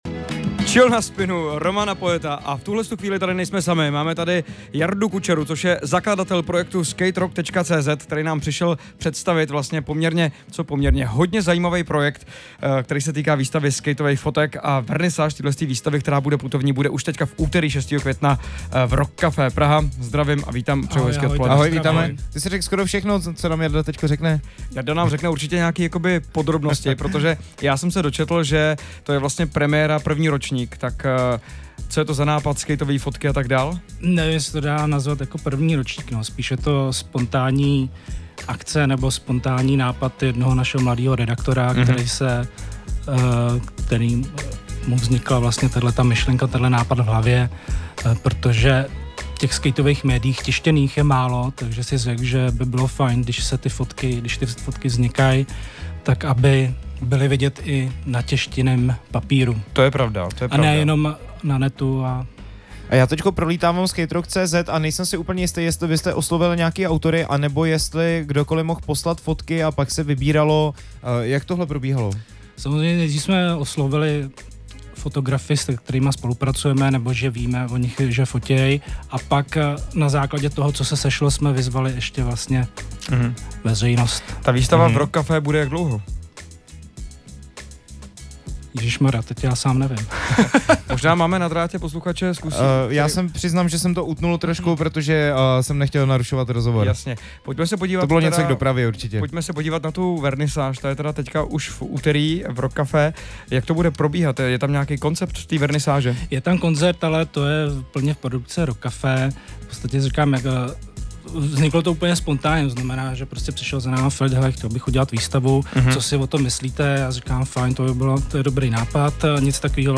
rozhovor_spin.mp3